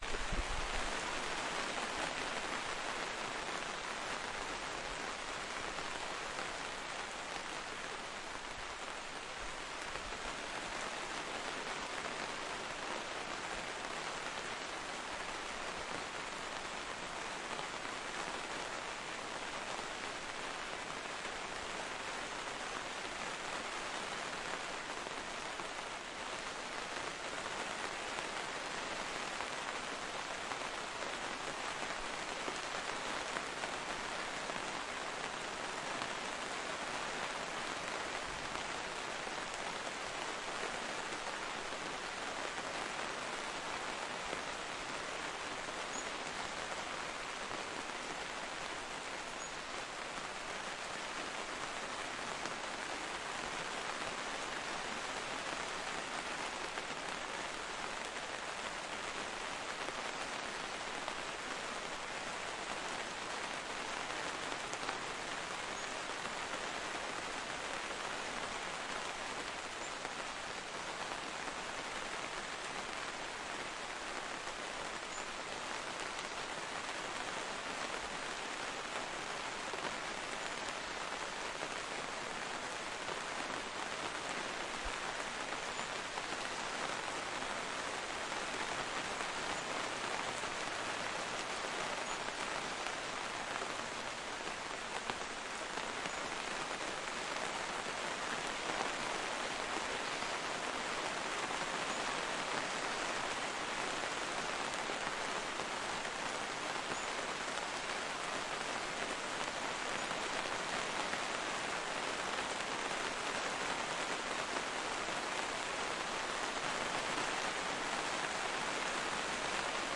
暴雨和暴风雪
描述：这是上周六下午，巴塞罗那附近暴雨的一个片段。除了下雨和雷声之外，你可能会听到那些试图避雨和一些车辆的人。
Tag: 风暴 天气 现场记录